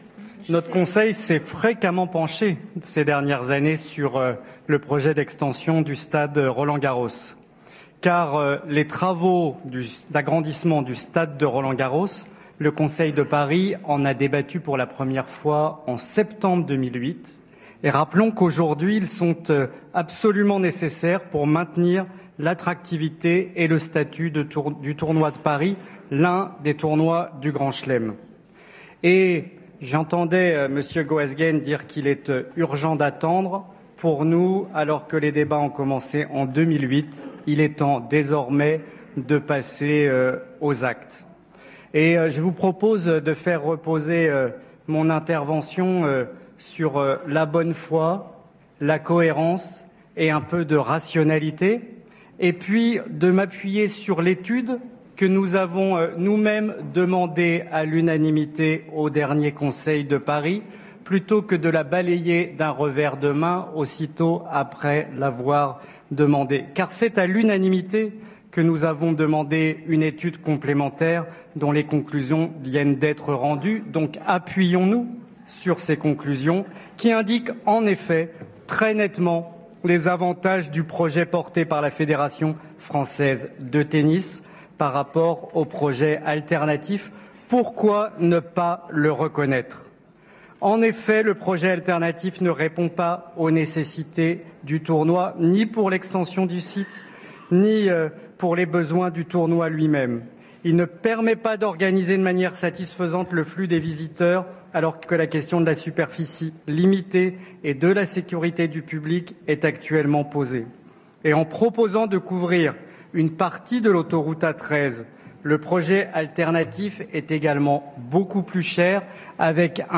Intervention de Rémi Féraud
Intervention de Rémi Féraud lors du débat organisé sur l'extension de Roland Garros.mp3 (5.43 Mo)